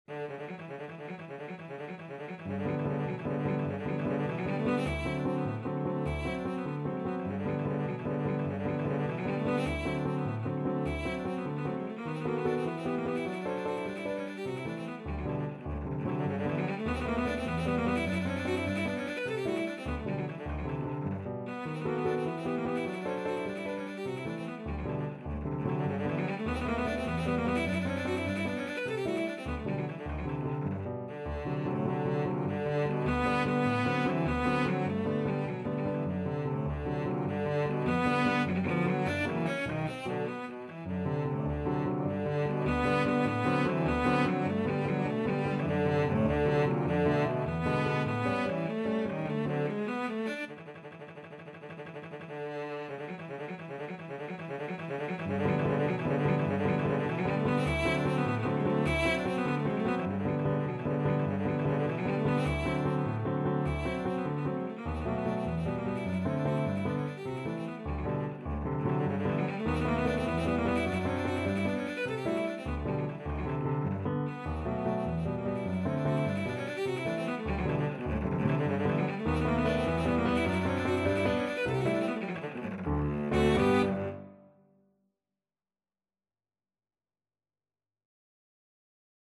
CelloPianoCello
3/4 (View more 3/4 Music)
Molto vivace .=100
Cello  (View more Advanced Cello Music)
Classical (View more Classical Cello Music)